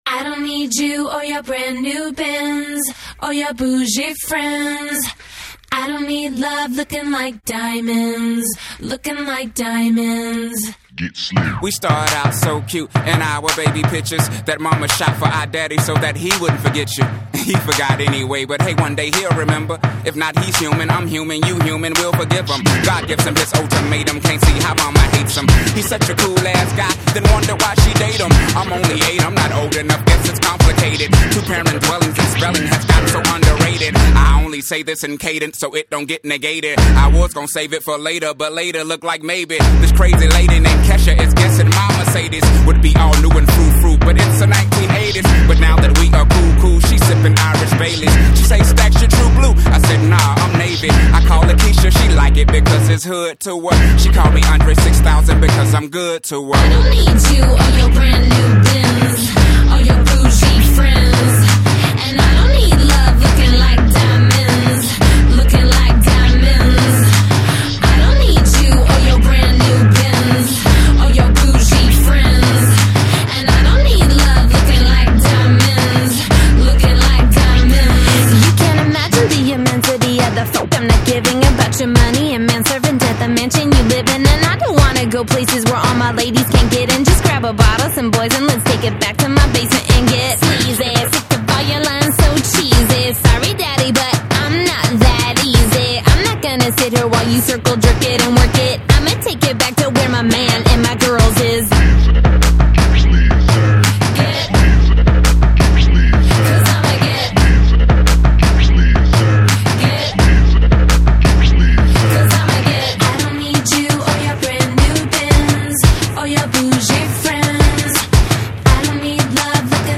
While this is a decent pop song